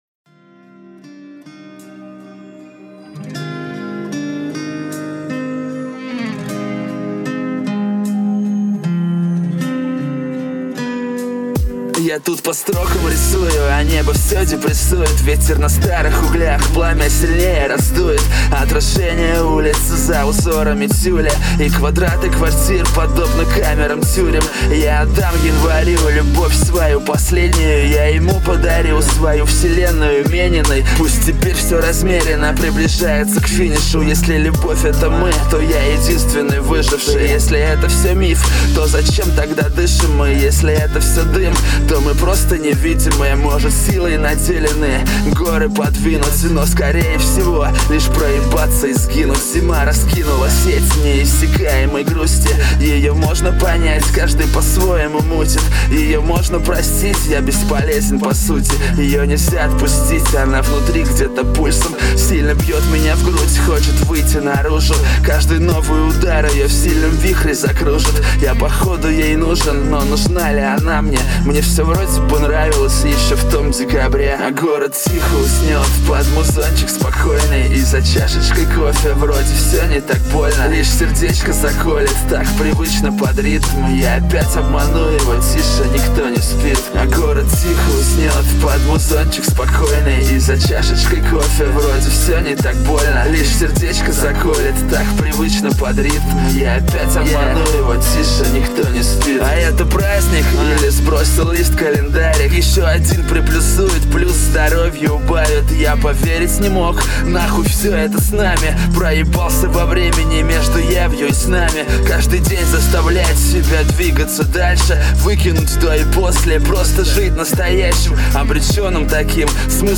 Музыкальный хостинг: /Рэп